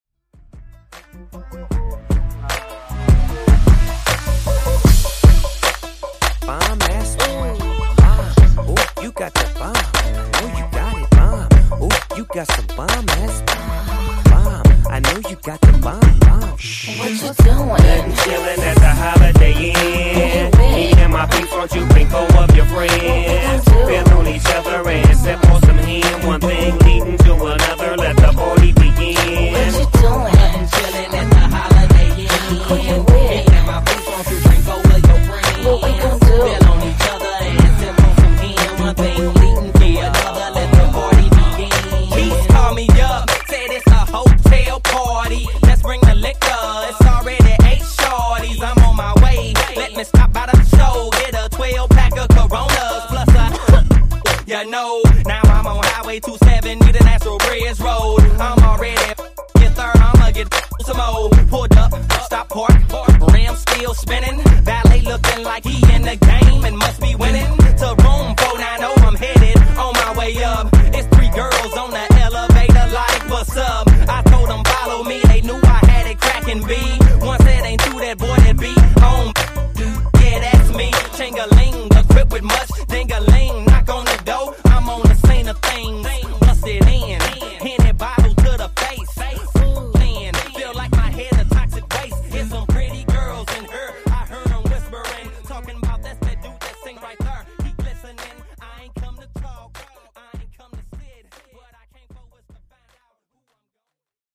Genre: RE-DRUM Version: Dirty BPM: 107 Time